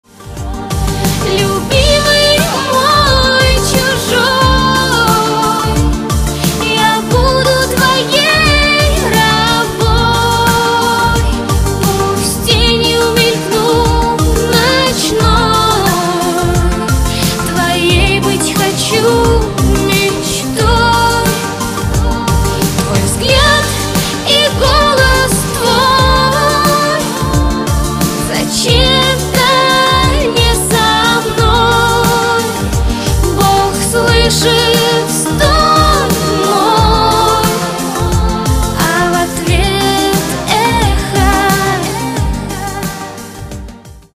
Категория: Попса